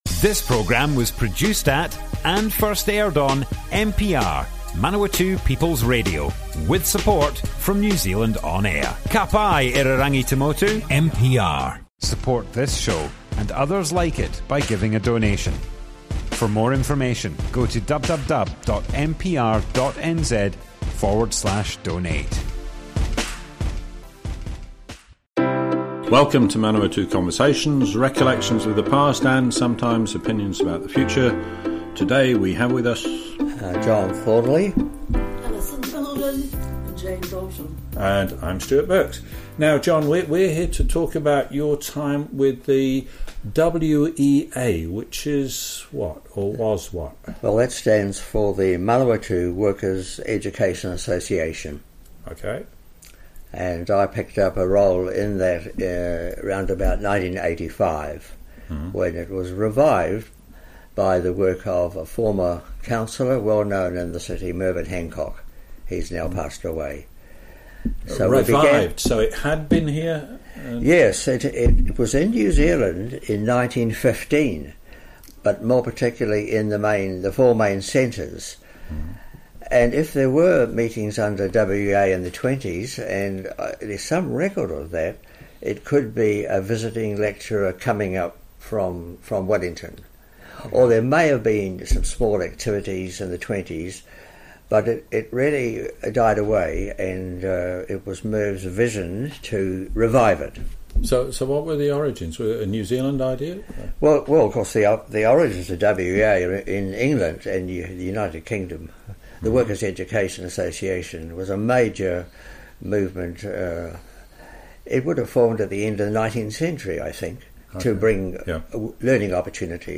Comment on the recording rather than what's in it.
Manawatu Conversations More Info → Description Broadcast on Manawatu People's Radio, 5th May 2020. Workers Education Association in NZ from 1915, but died away.